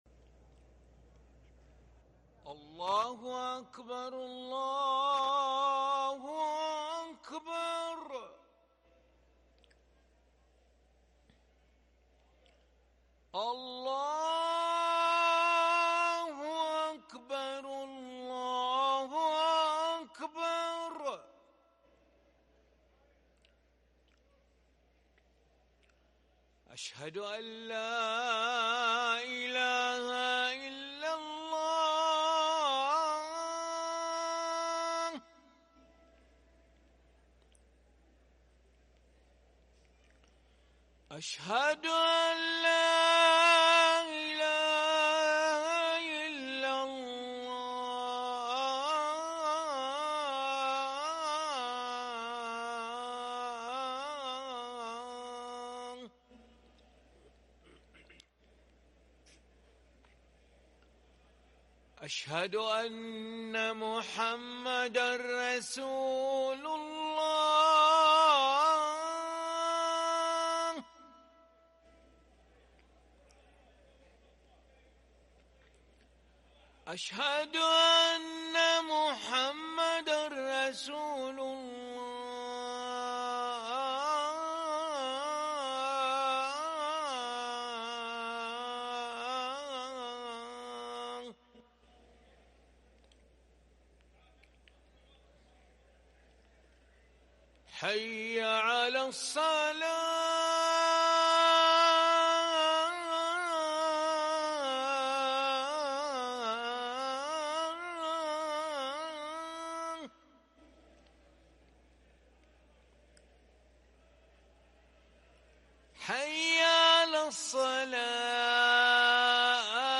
أذان العشاء للمؤذن علي ملا الخميس 3 ربيع الأول 1444هـ > ١٤٤٤ 🕋 > ركن الأذان 🕋 > المزيد - تلاوات الحرمين